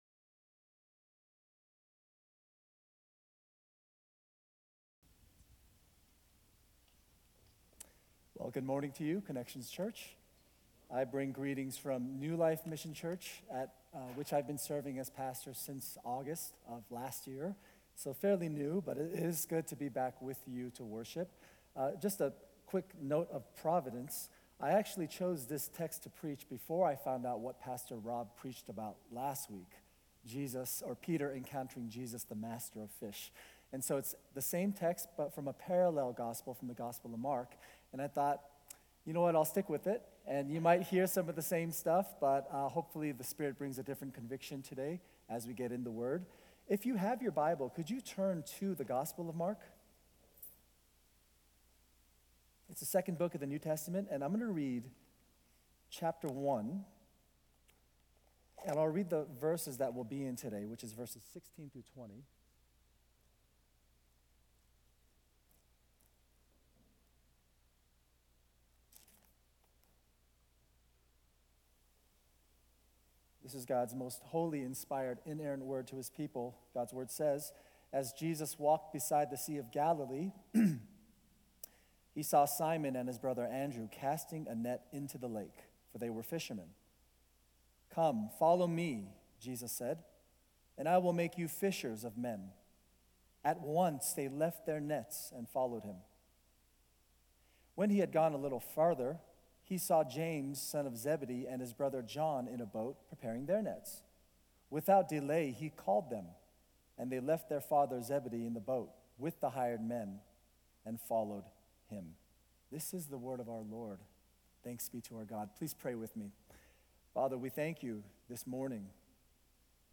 A message from the series "Guest Speaker."